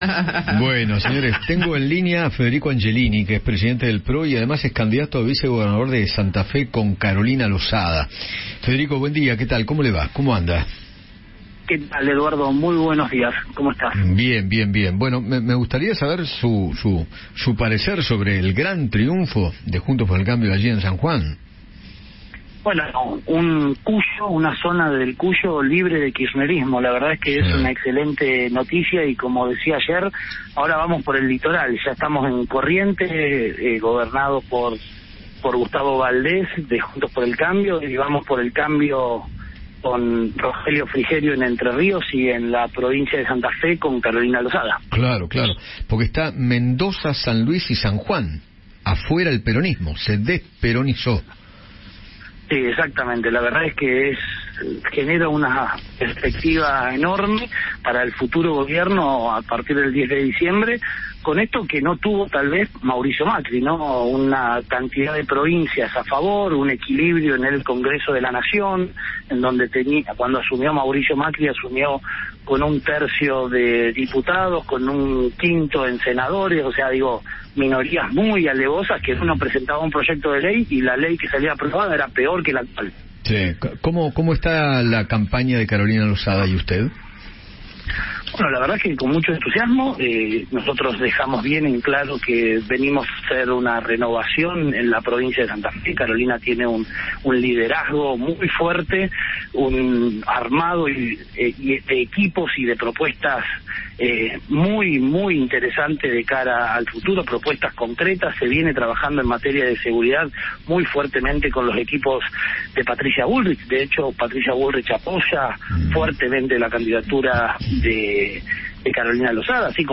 Federico Angelini, diputado y presidente del PRO, habló con Eduardo Feinmann sobre los resultados electorales en las provincias dando victorias para Juntos por el Cambio.